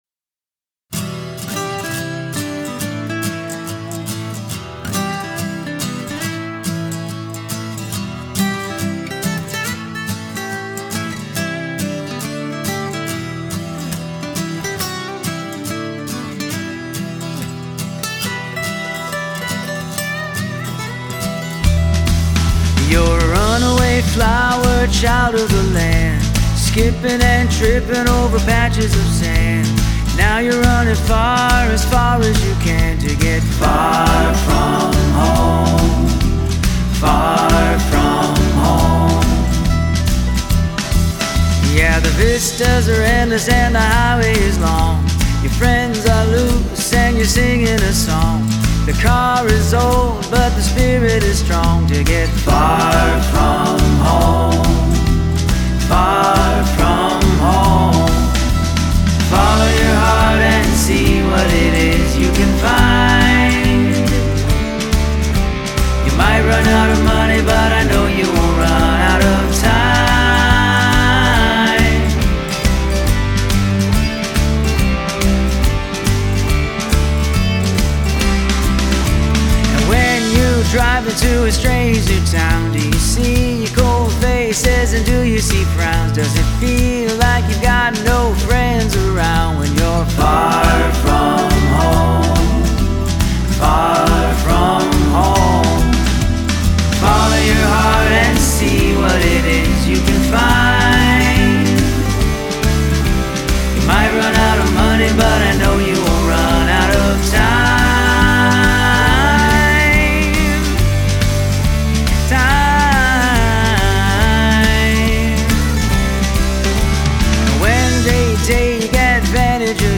The original style Style I used was _TOUGHIT.STY (Tough It Swinging Funk Rock).
Very well sung, very well mixed and produced.
Excellent guitar work.
Wow, this sounds like a real band, very well performed and recorded.
Good vocals, background vocals, guitars and other instruments.